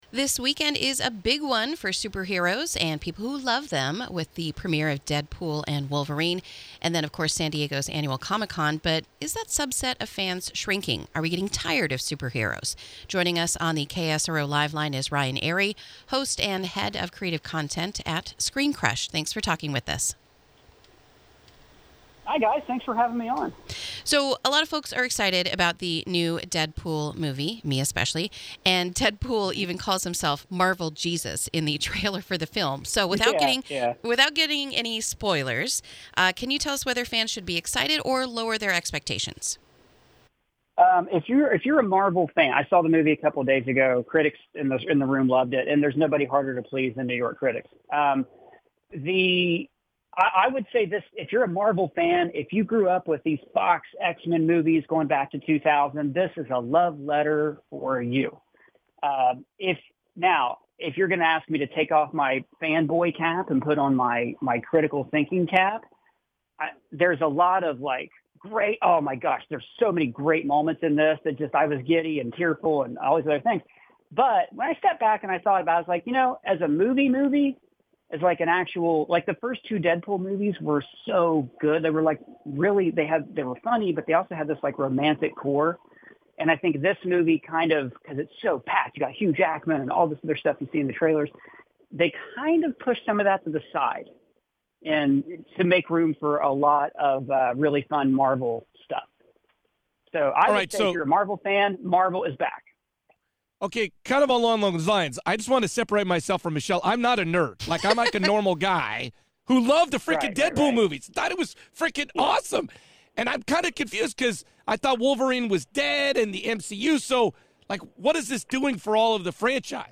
INTERVIEW: Deadpool and Wolverine with ScreenCrush